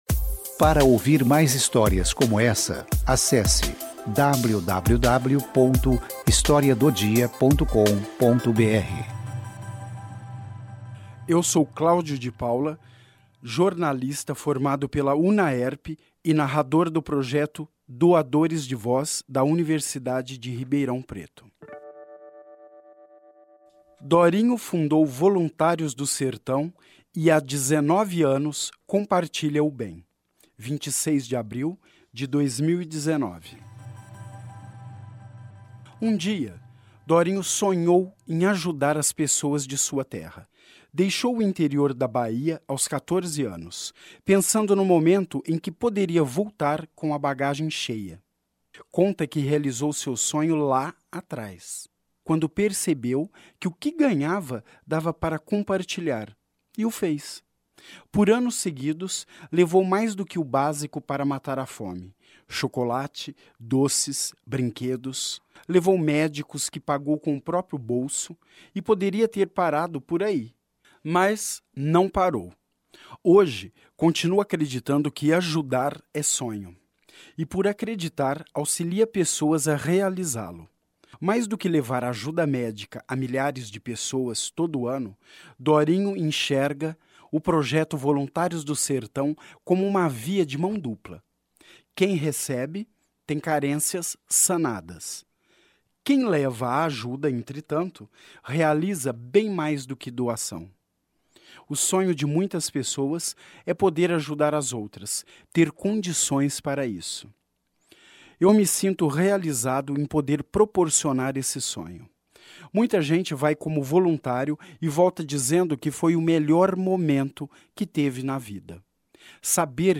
Esta história foi narrada pelos integrantes do projeto Doadores de Voz, dos cursos de Jornalismo e Publicidade e Propaganda da Unaerp.